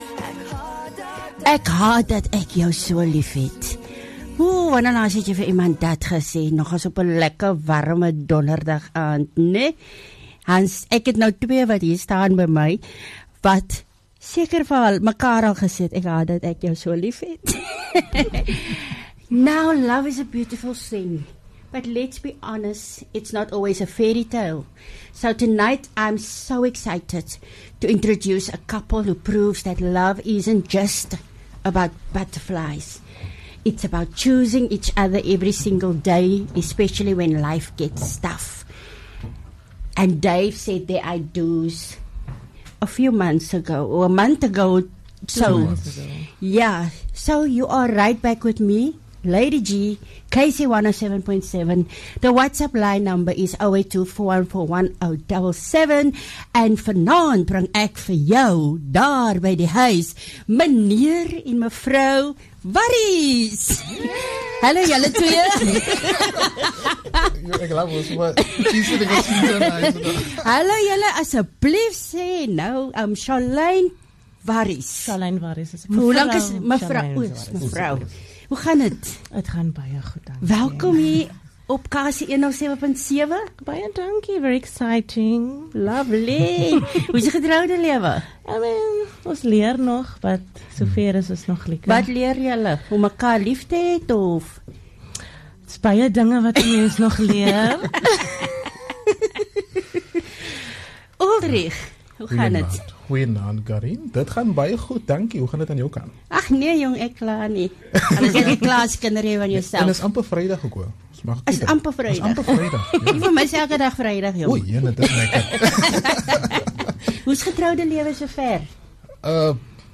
3 Jul Empowering interview with a newly married couple